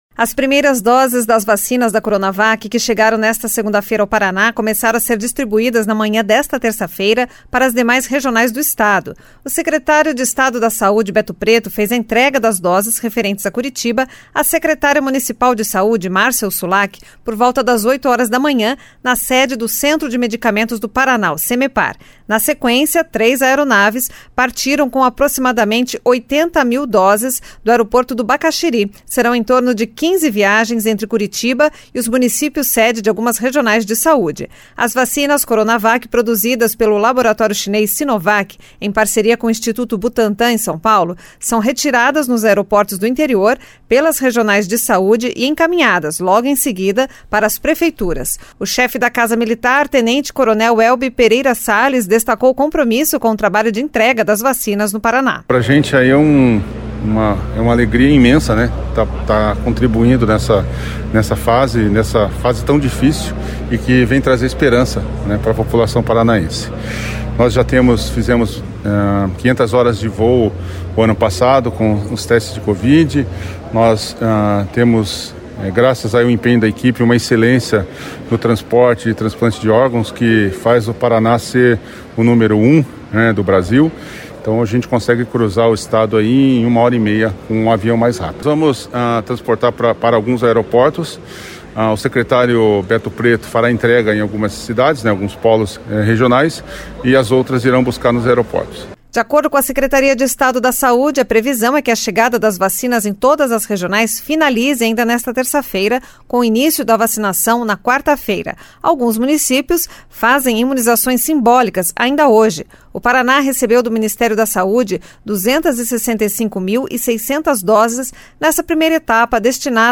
O chefe da Casa Militar, tenente-coronel Welby Pereira Sales, destacou o compromisso com o trabalho de entrega das vacinas no Paraná.